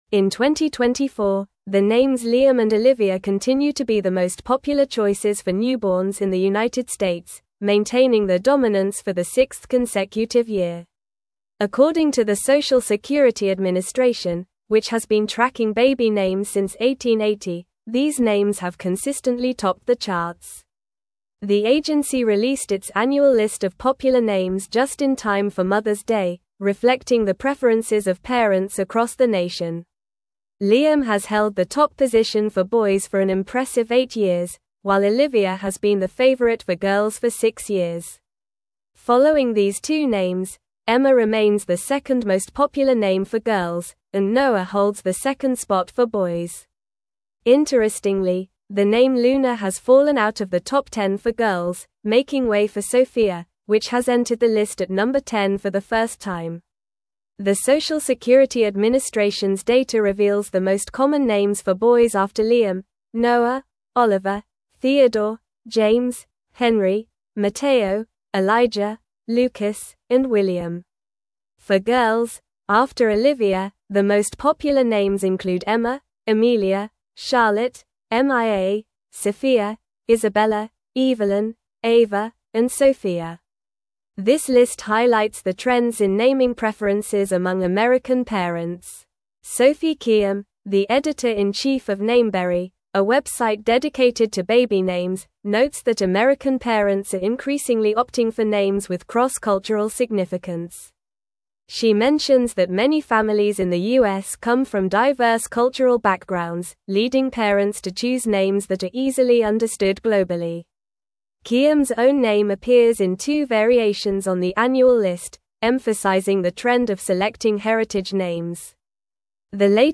Normal
English-Newsroom-Advanced-NORMAL-Reading-Liam-and-Olivia-Remain-Top-Baby-Names-for-2024.mp3